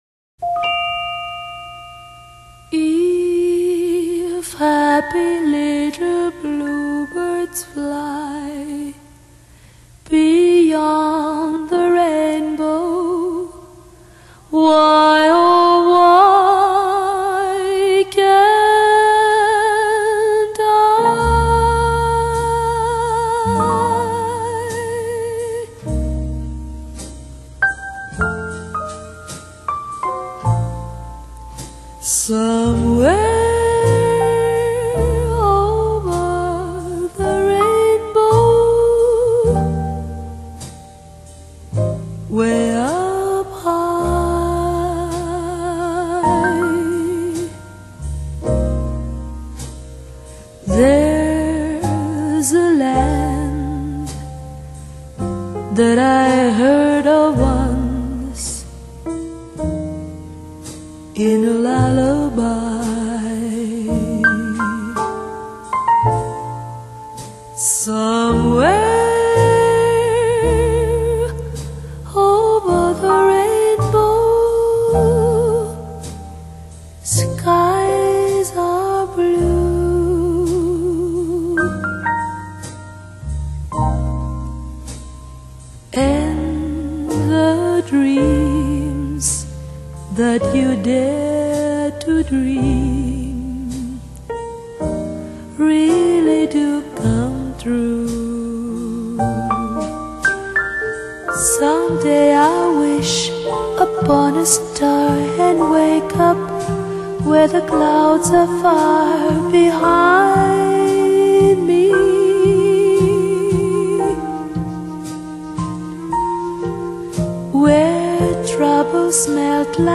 西洋音樂
清幽淡雅的歌聲韻味